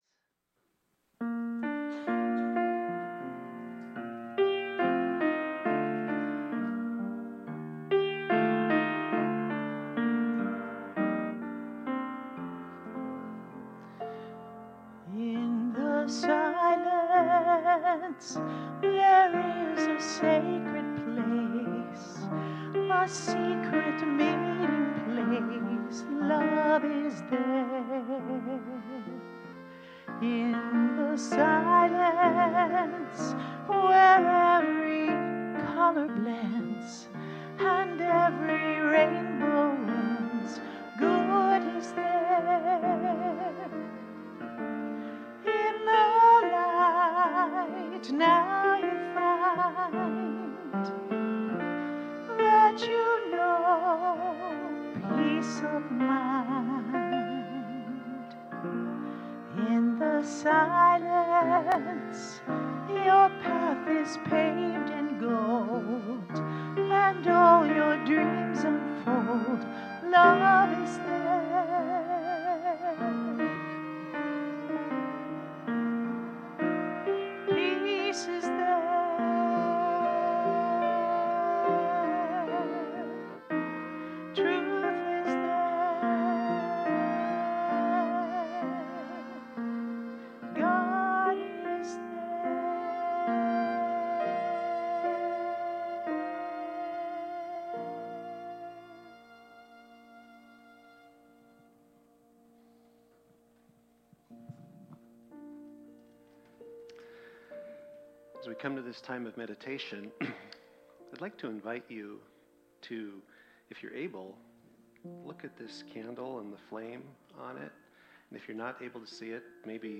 The audio recording (below the video clip) is an abbreviation of the service. It includes the Meditation, Message, and Featured Song.